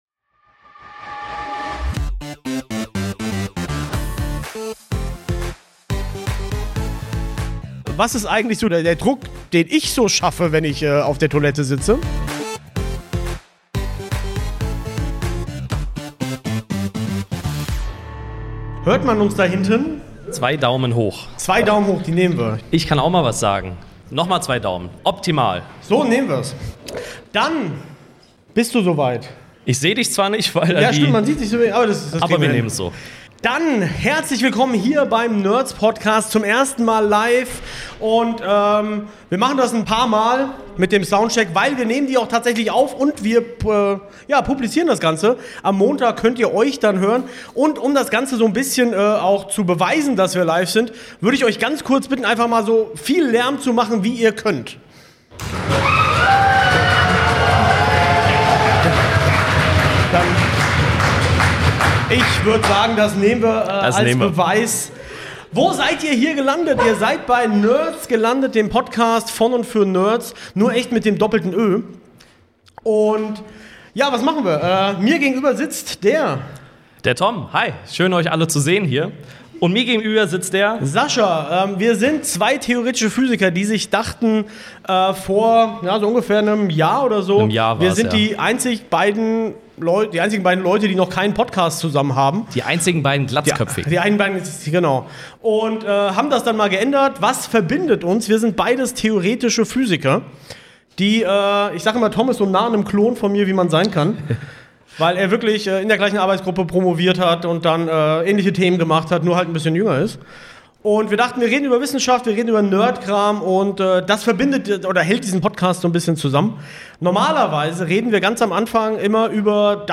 Beschreibung vor 9 Monaten Die NÖÖRDS waren live auf der Night of Science!
Es geht um Photonen und Pinguine. Da wir live in einem Hörsaal waren haben wir ein paar Folien an die Wand geworfen, das Material ist aus den Papern. Vielen Dank nochmal an das Publikum, ihr wart großartig!